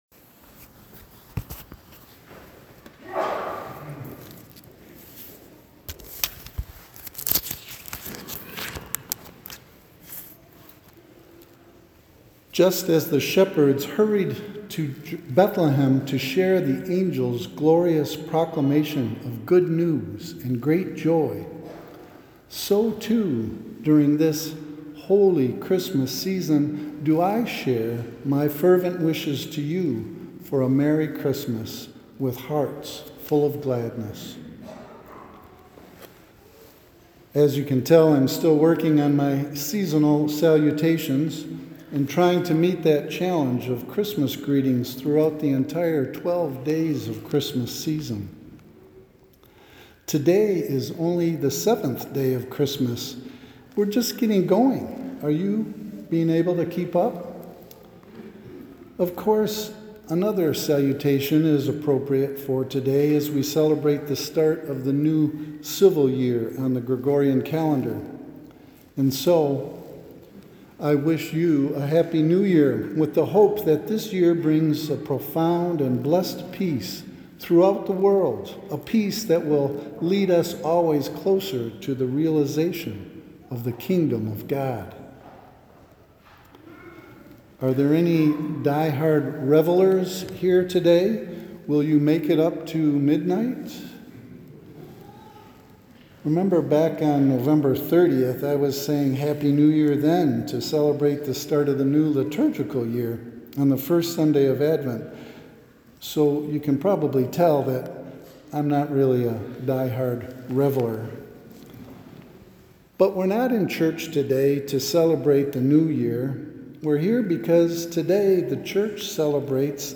Homilist